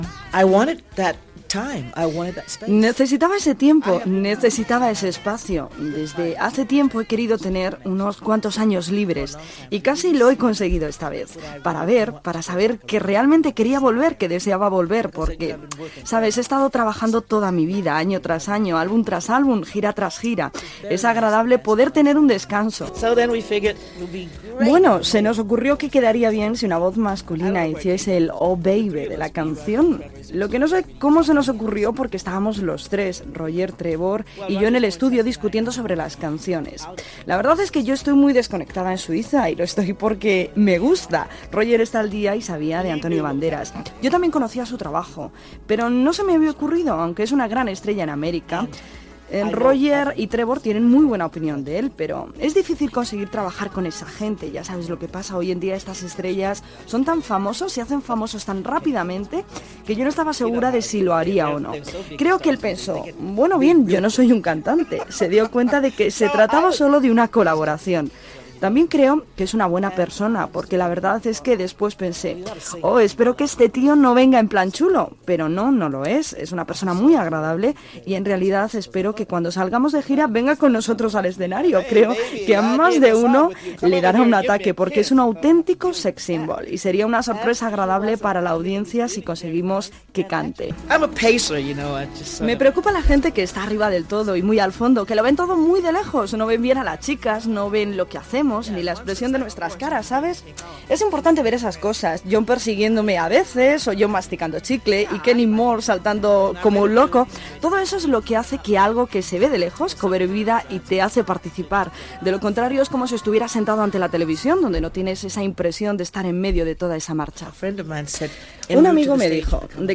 Especial Tina Turner amb una entrevista en anglès a la cantant
Musical